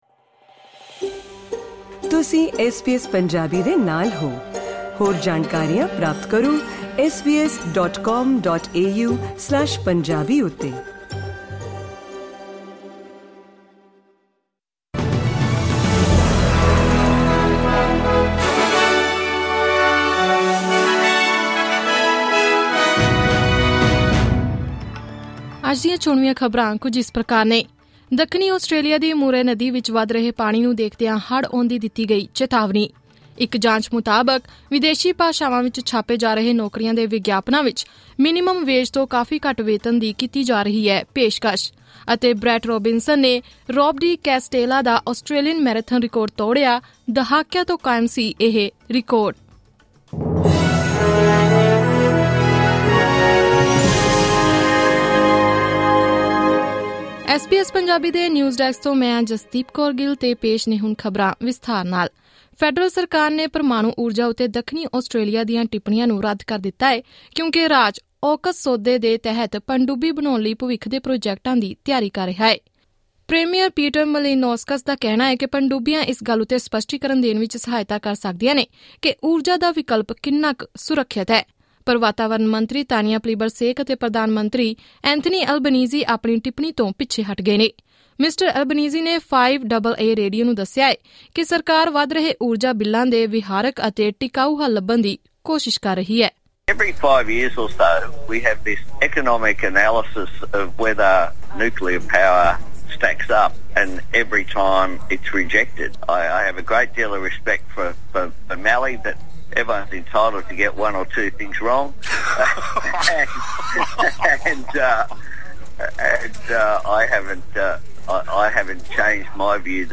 SBS Punjabi Australia News: Monday 5 December 2022